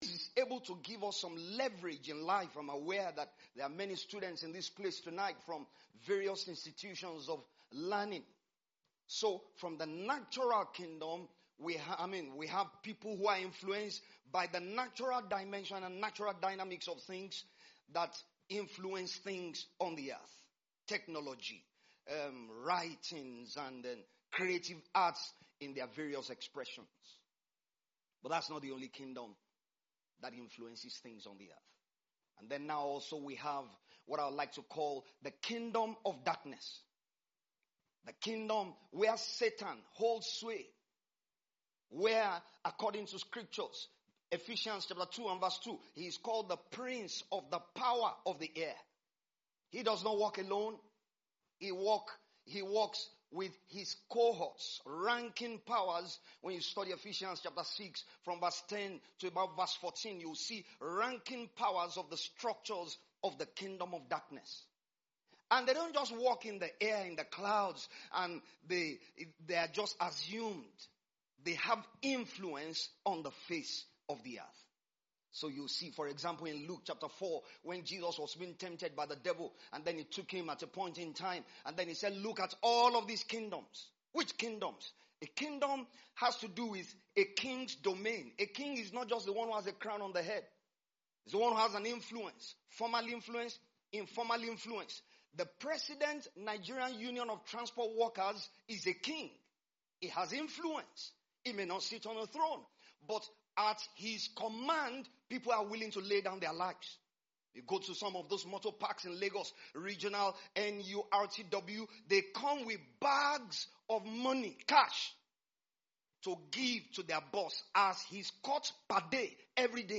IRC PHC 2025 DAY 1 EXHORTATION - Voice of Truth Ministries